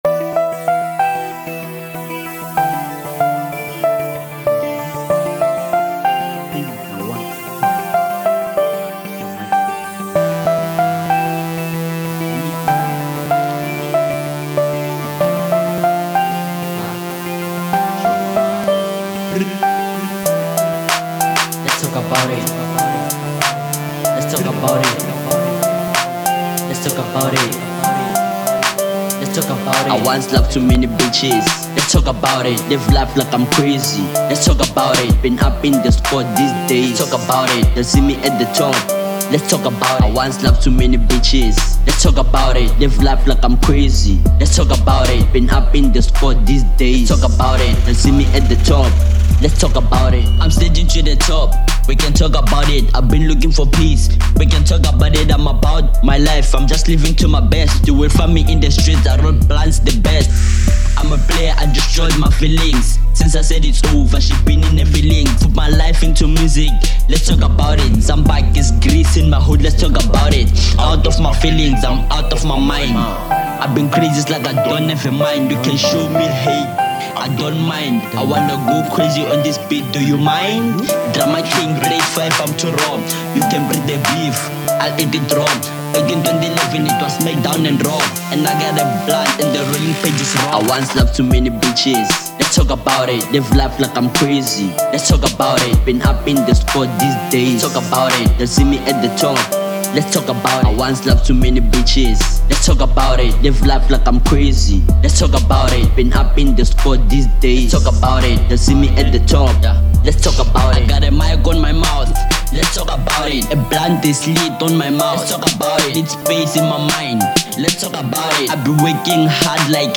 03:40 Genre : Hip Hop Size